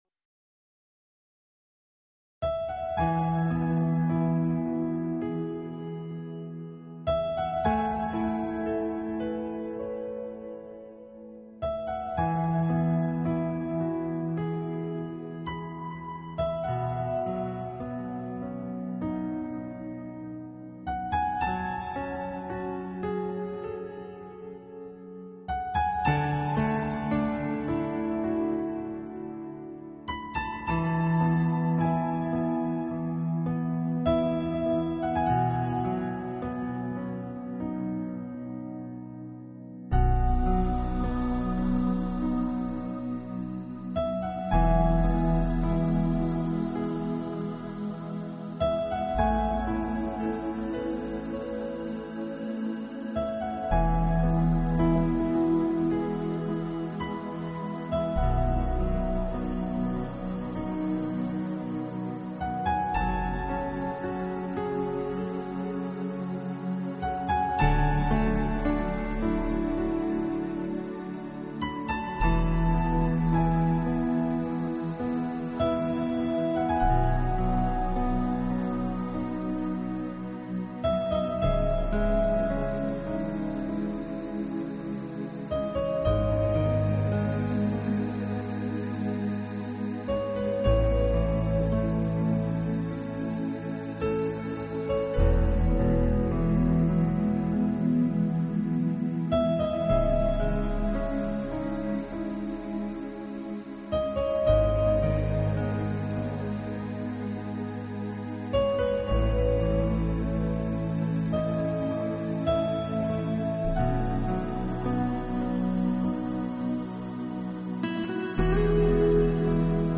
音乐风格：New Age